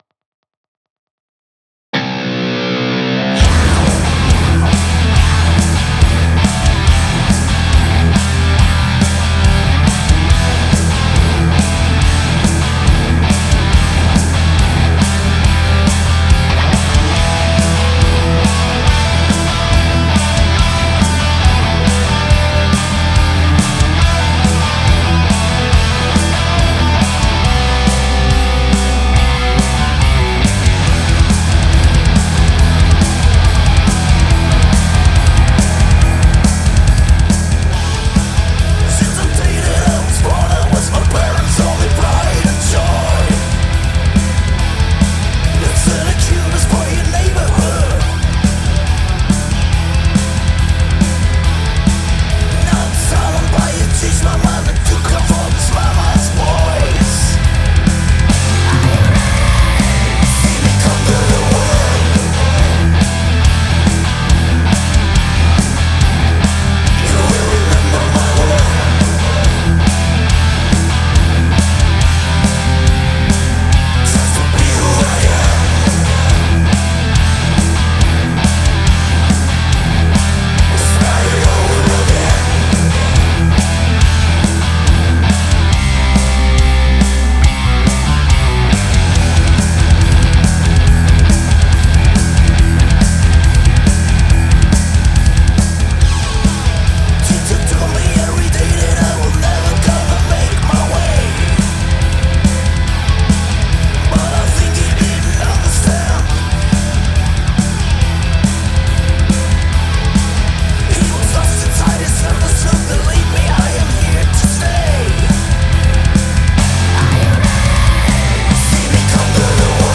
Ich habe mich mit Home-Recording und eigenen Songs beschäftigt.
Ich habe mich jedoch ewig im Kreis gedreht, habe einen Heavy-Mix einfach nicht hinbekommen.